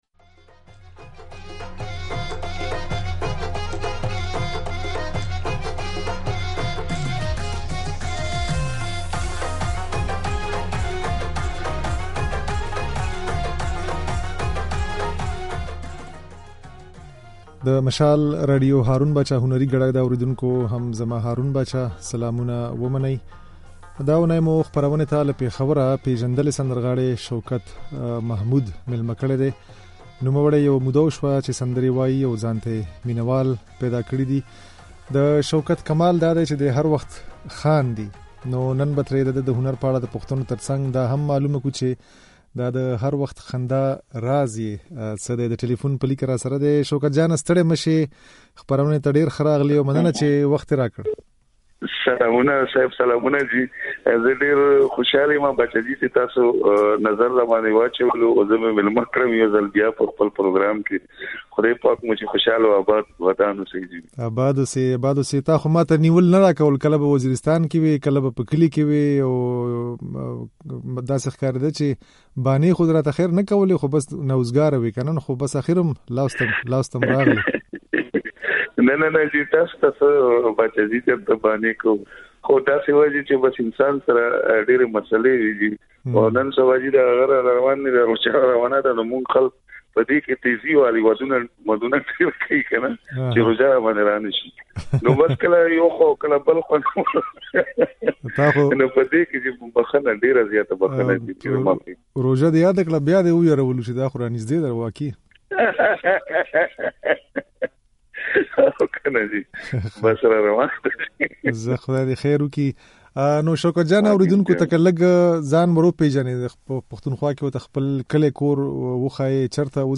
خبرې او ځينې سندرې يې د غږ په ځای کې اورېدای شئ.